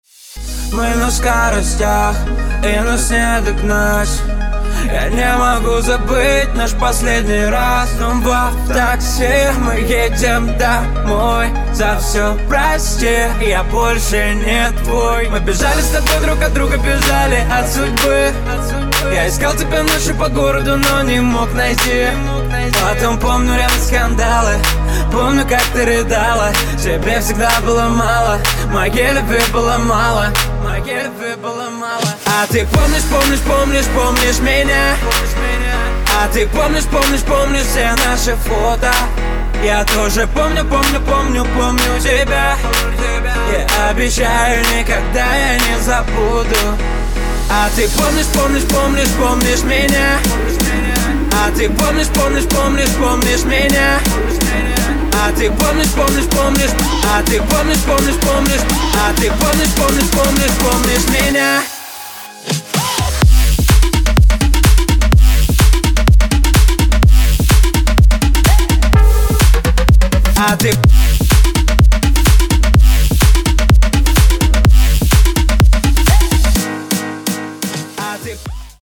• Качество: 192, Stereo
мужской вокал
dance
Electronic
EDM
Club House
электронная музыка
спокойные
клавишные
house